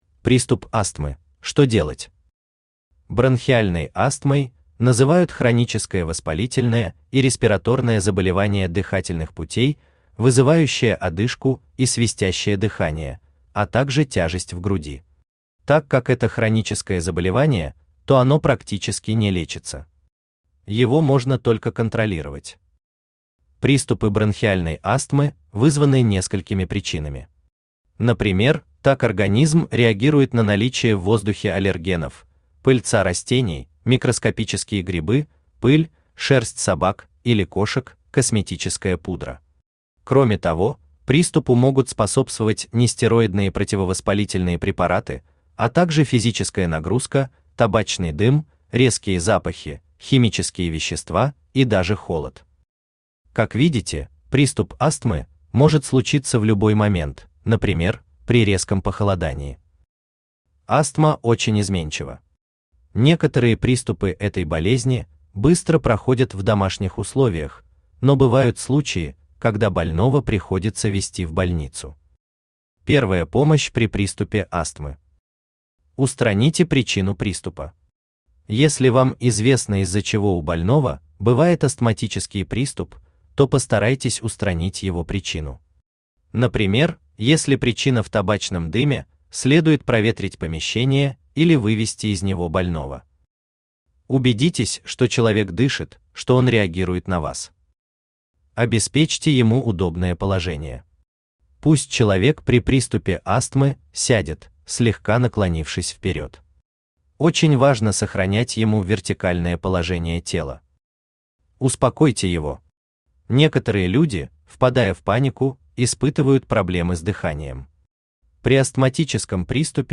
Том 5 Автор Геннадий Анатольевич Бурлаков Читает аудиокнигу Авточтец ЛитРес.